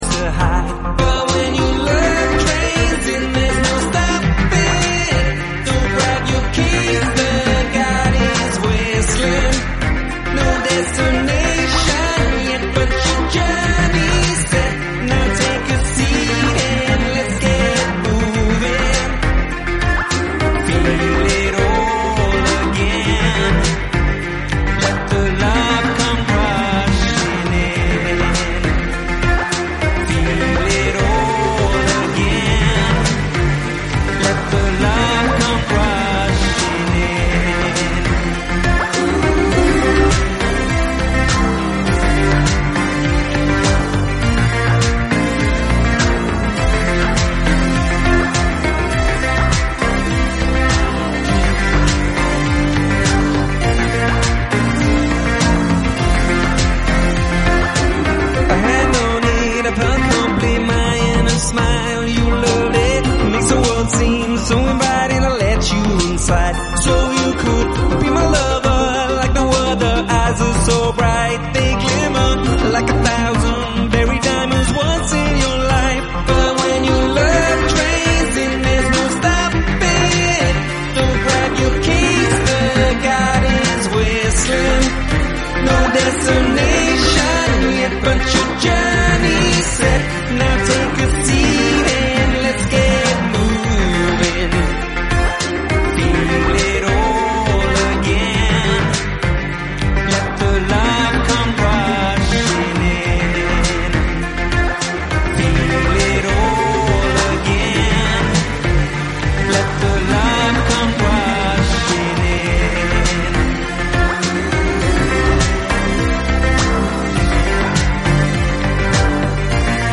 im typischen 80er Sound